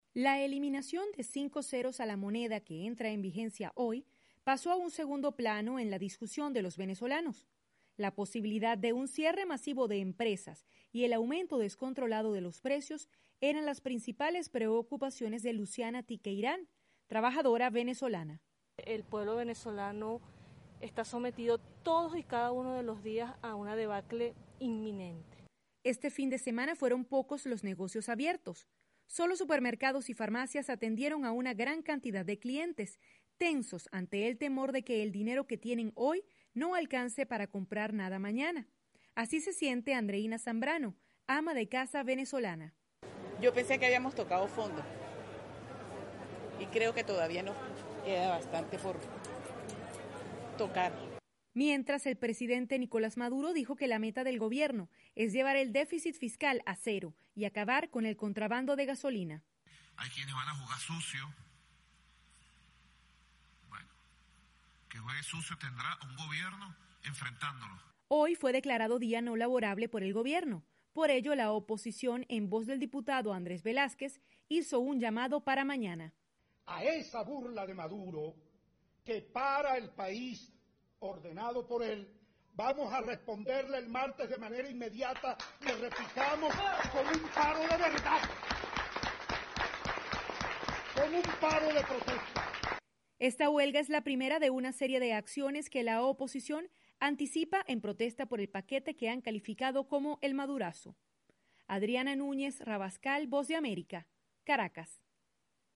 VOA: Informe de Venezuela